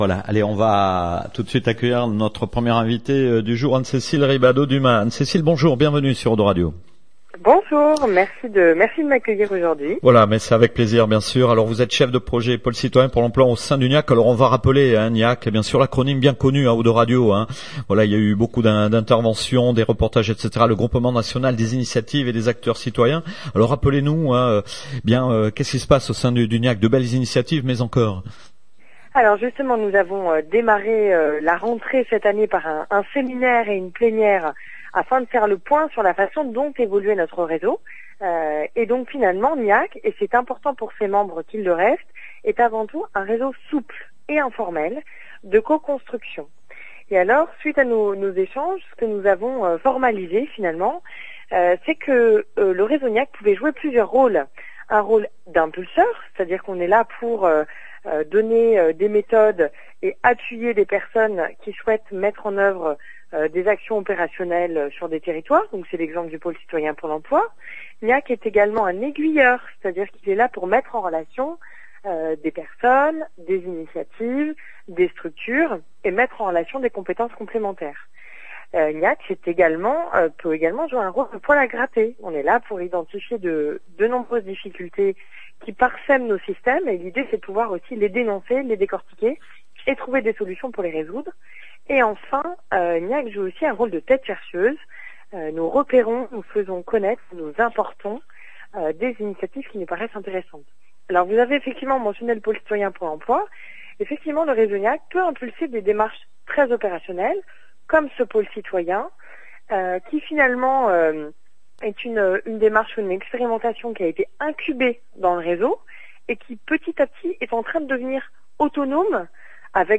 19/10/2016 : Nouvel Interview GNIAC / O2 Radio